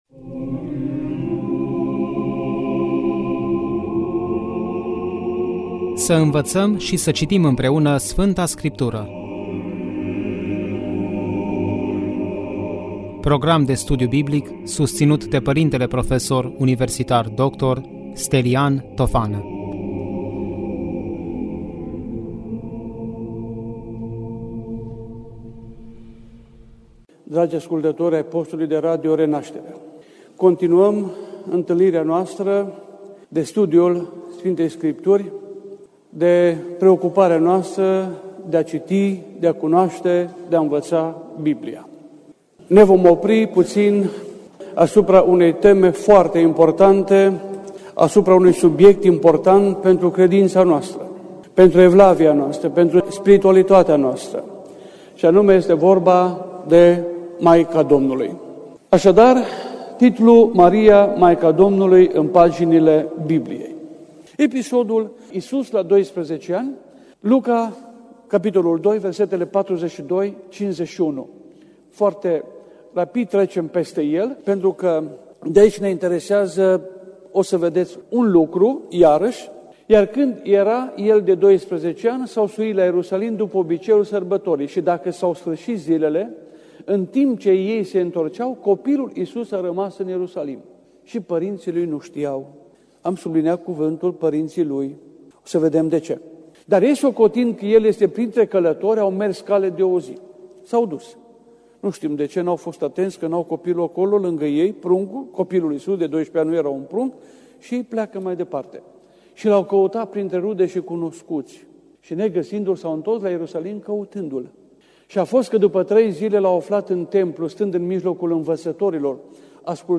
Studiu Biblic Maica Domnului în Sfânta Scriptură